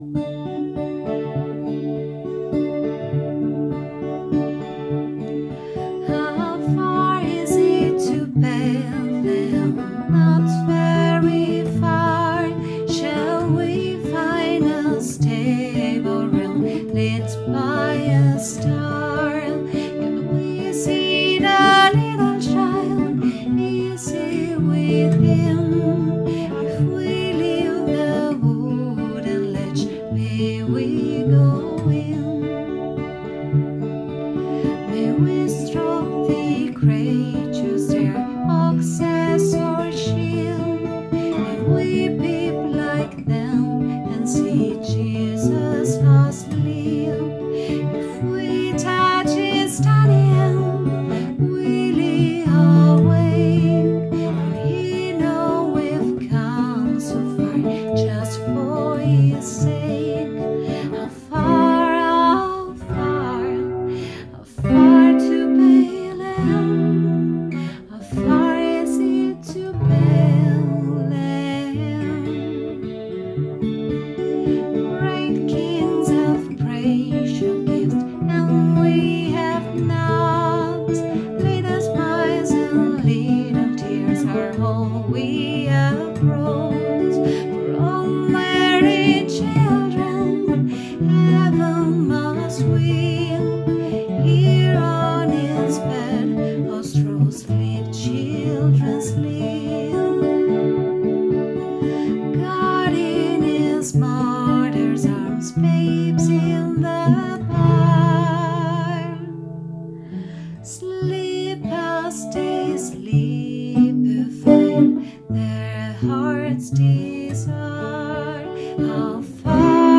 Questa poesia di Frances in Inghilterra è diventata una famosa Christmas Carol.